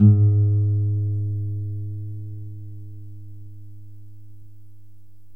西班牙吉他音符 " E2
描述：西班牙吉他的E2音符。未加工。
Tag: 古典 尼龙 尼龙弦 西班牙吉他